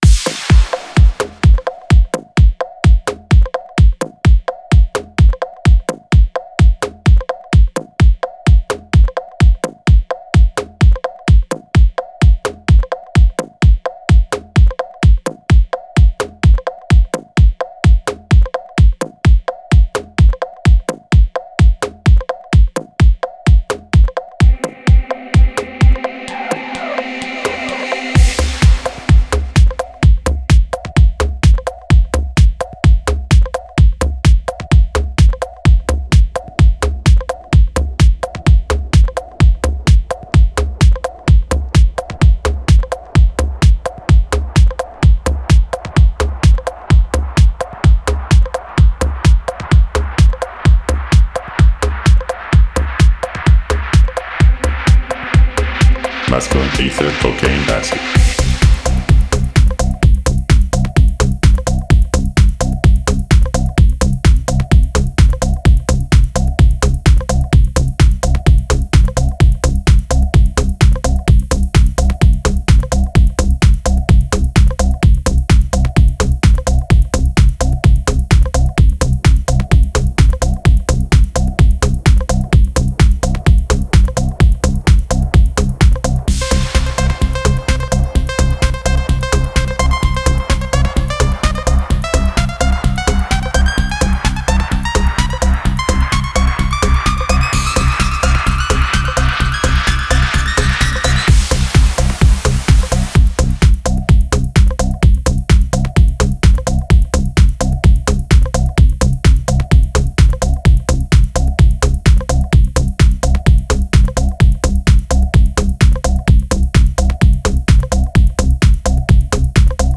минимал